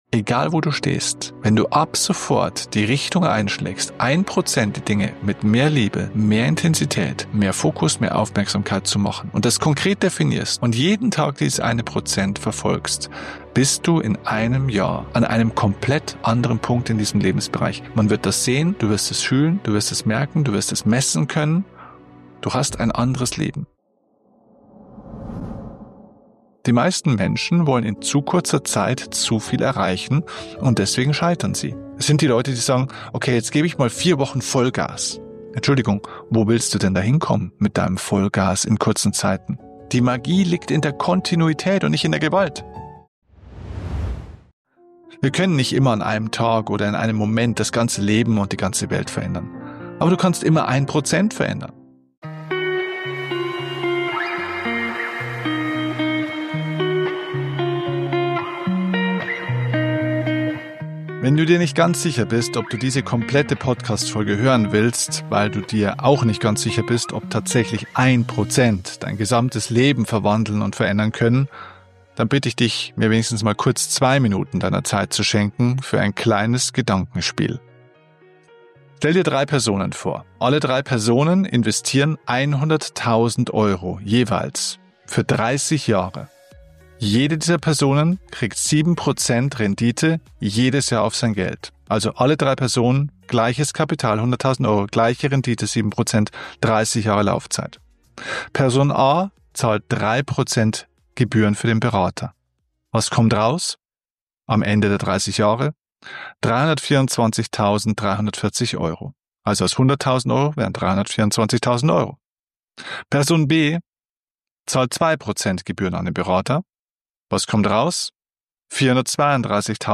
Wie immer ohne Skript, ohne Filter – einfach zwei Freunde, die Klartext reden und ihre Erfahrungen teilen.